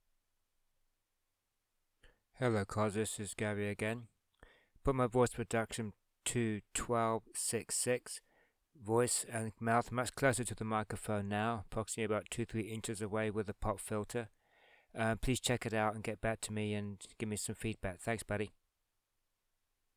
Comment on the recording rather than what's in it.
Also is a audio sample with my mouth much closer to the microphone and with noise reduction set to 12.6,6.